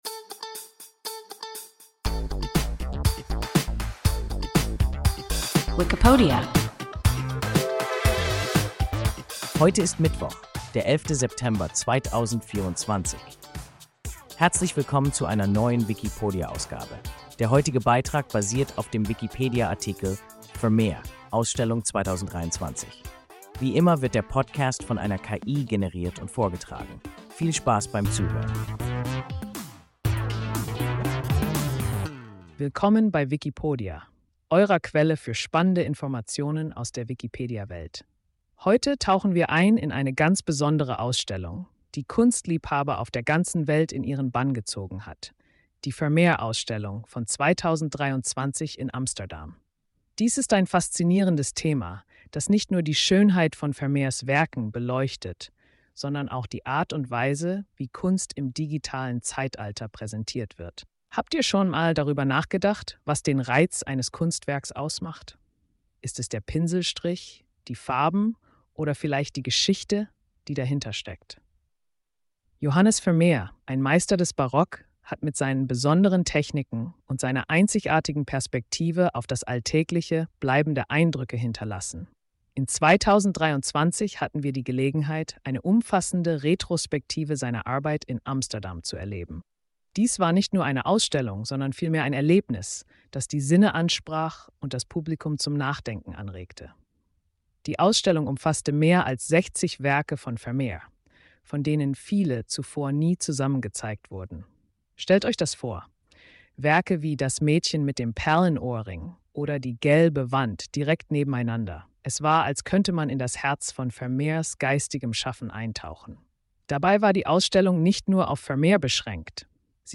Vermeer (Ausstellung 2023) – WIKIPODIA – ein KI Podcast